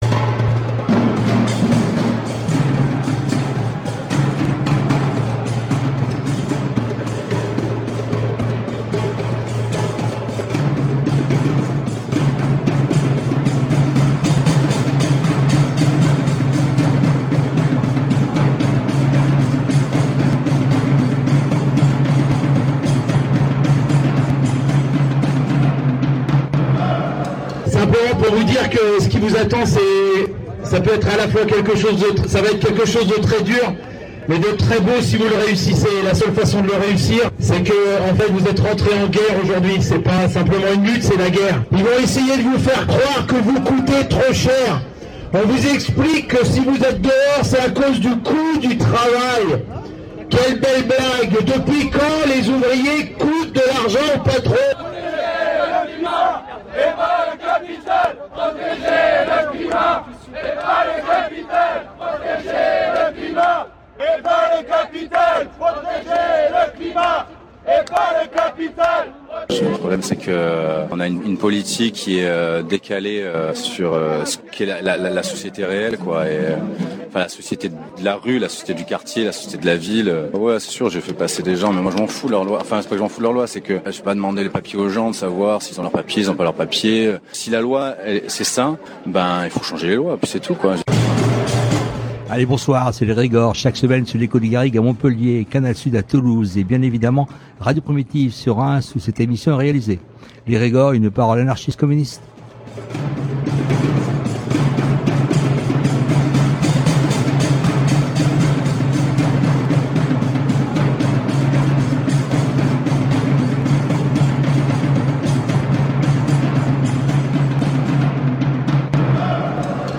Un entretien autour de sa vie, de son œuvre et de ses engagements politiques